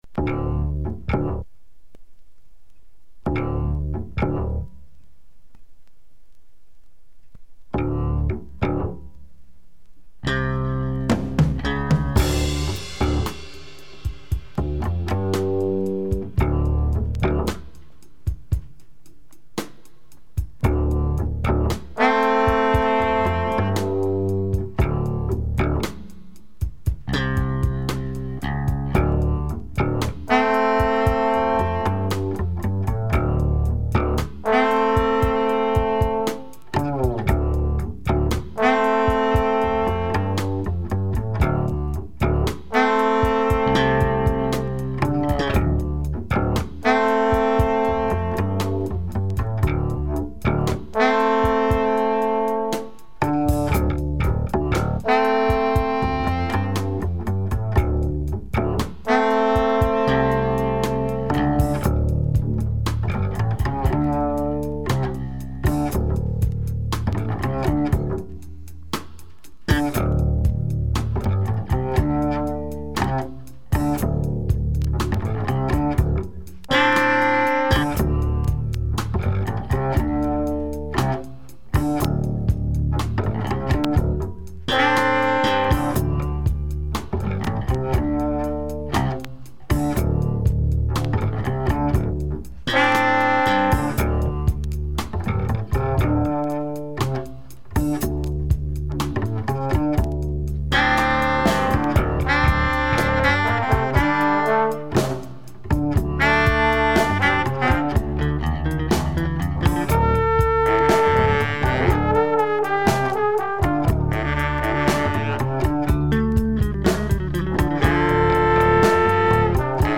underground / autoproduced jazz album
and it's all about deep jazz groove with drumbreaks
or experimental / avant garde.